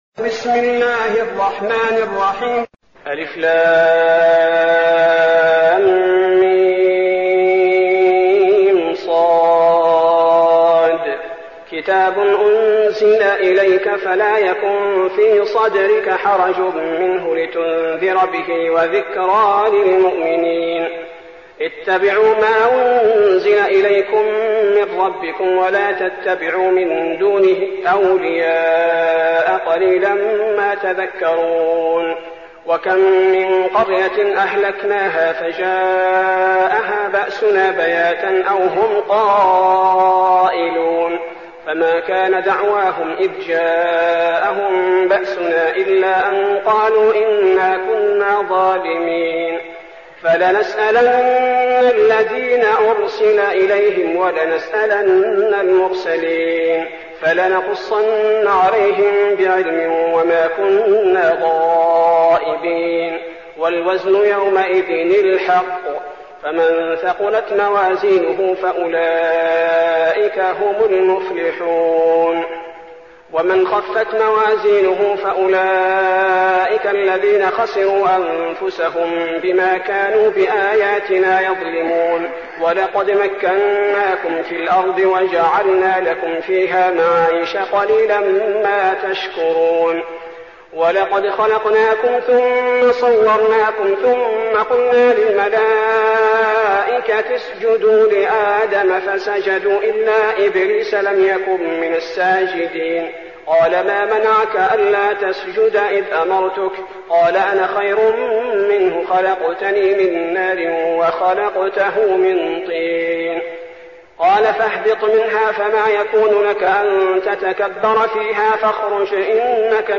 المكان: المسجد النبوي الشيخ: فضيلة الشيخ عبدالباري الثبيتي فضيلة الشيخ عبدالباري الثبيتي الأعراف